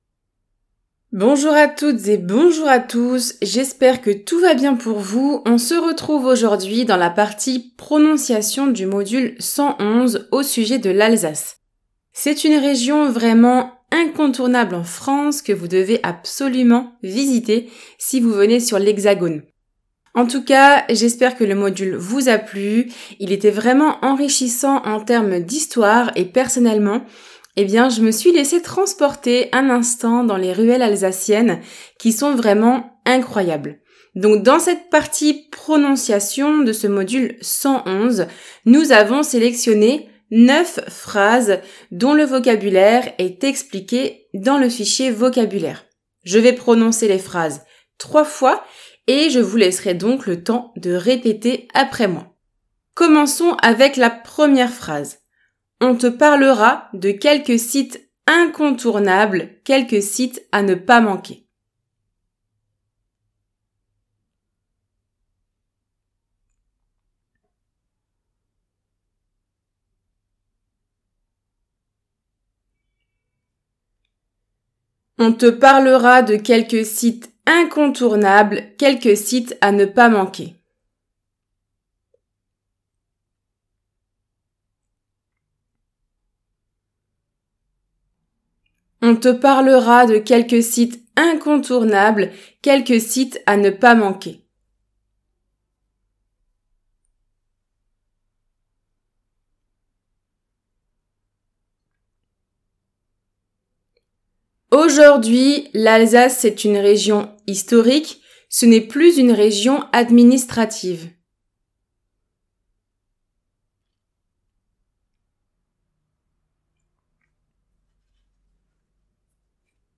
Prononciation
module_111_lalsace_pro.mp3